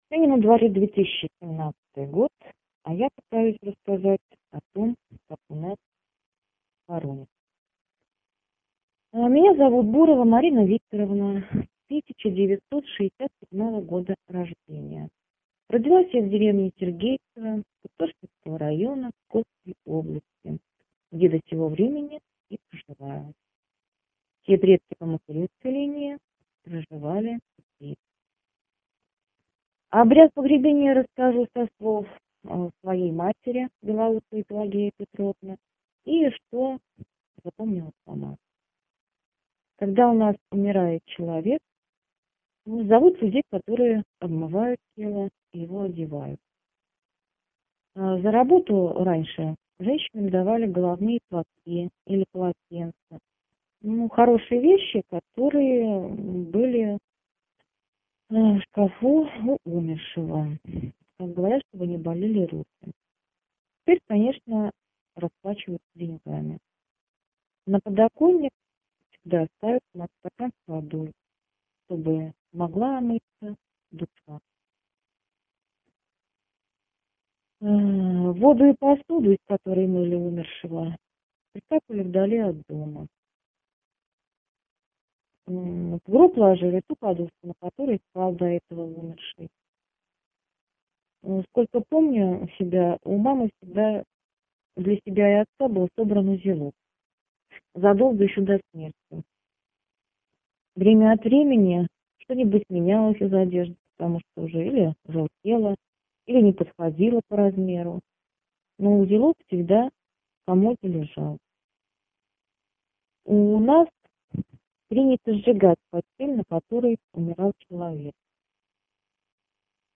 Воспоминания